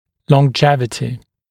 [lɔn’ʤevətɪ][лон’джевэти]долголетие; долгожительство, долговечность; продолжительность жизни